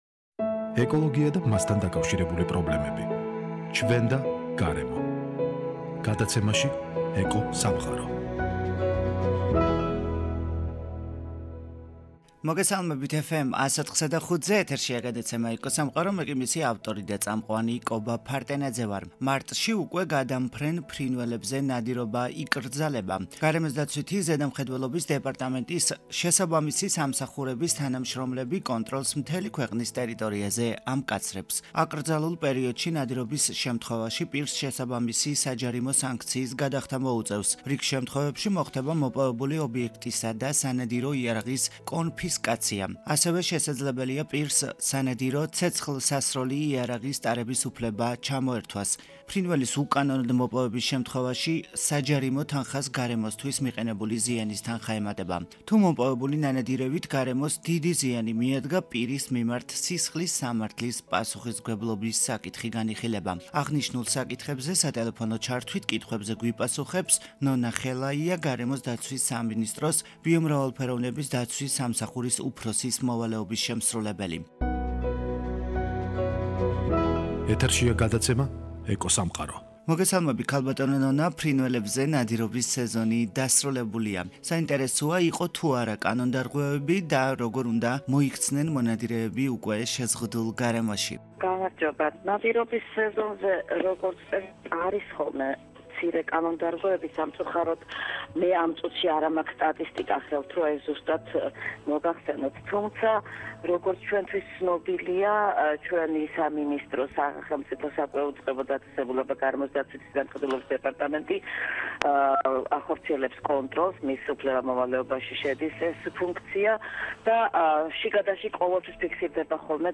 სატელეფონო ჩართვით